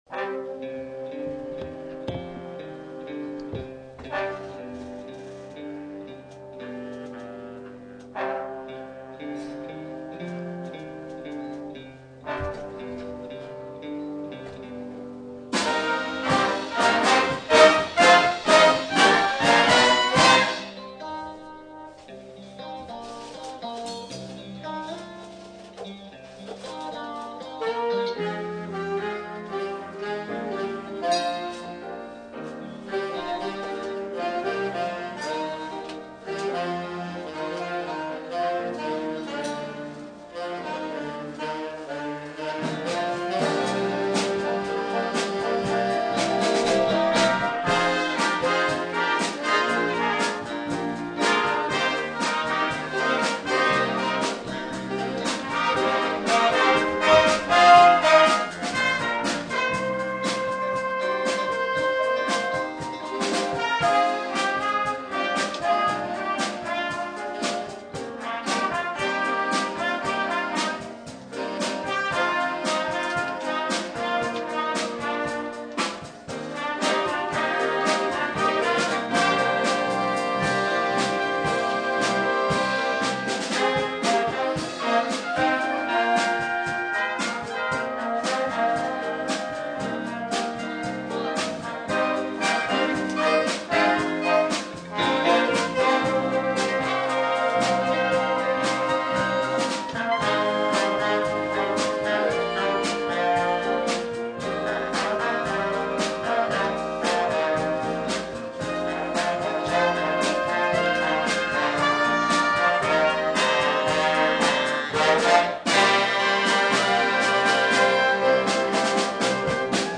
Aufnahmen einer Big Band Probe an diesem Stück sind hier zu hören.